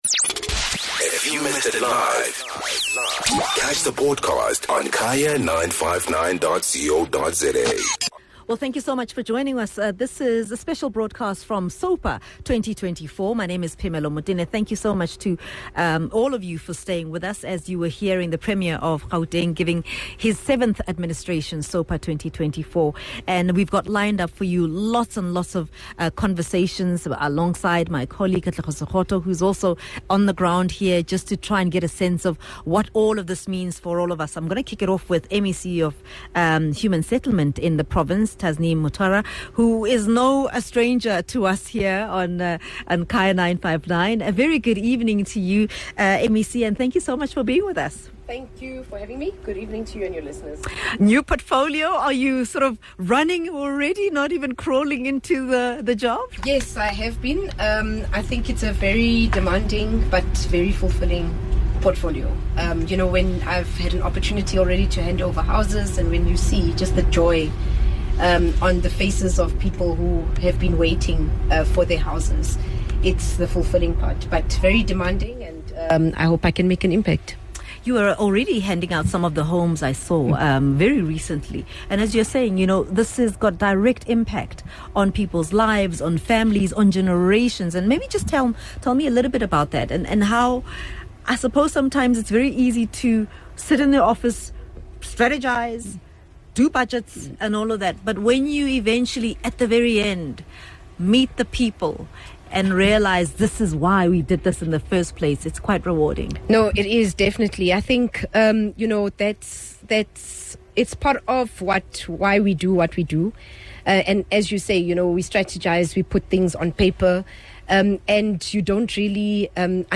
Point of View comes to you live from the Gauteng State of the Province address in Katlehong, Ekurhuleni. Today marks the official opening of the Gauteng Legislature for the 7th administration. Premier Panyaza Lesufi outlined the provincial government’s action plan under the government of national unity for the next five years.